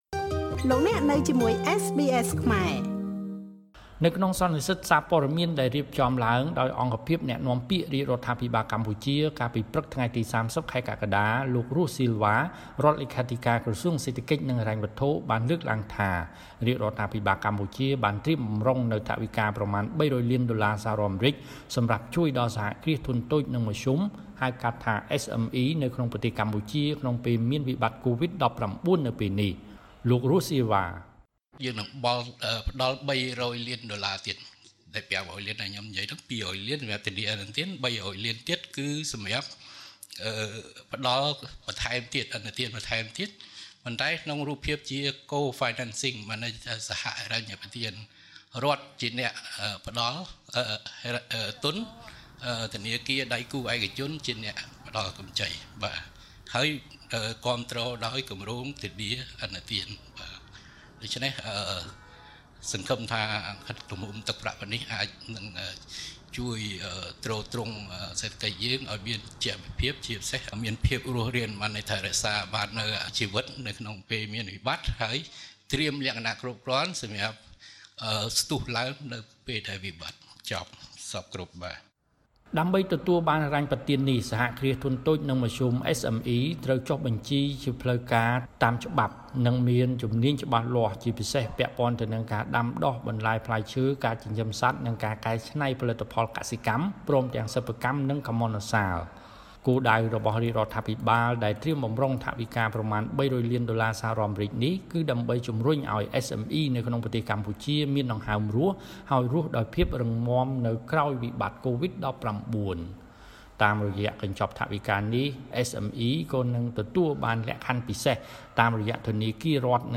ក្នុងសន្និសីទសារព័ត៌មាន ដែលរៀបចំដោយអង្គភាពអ្នកនាំពាក្យរាជរដ្ឋាភិបាលកម្ពុជា កាលពីព្រឹកថ្ងៃទី៣០ ខែកក្កដា លោក រស់ ស៊ីលវ៉ា រដ្ឋលេខាធិការក្រសួងសេដ្ឋកិច្ច និងហិរញ្ញវត្ថុ បានលើកឡើងថា រាជរដ្ឋាភិបាលកម្ពុជា បានត្រៀមបម្រុងនូវថវិកាប្រមាណ ៣០០ លានដុល្លារសហរដ្ឋអាមេរិក សម្រាប់ជួយដល់សហគ្រាសធុនតូច និងមធ្យម ហៅកាត់ថា SME នៅកម្ពុជា ក្នុងពេលមានវិបត្តិកូវីដ នាពេលនេះ។